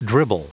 Prononciation du mot dribble en anglais (fichier audio)
Prononciation du mot : dribble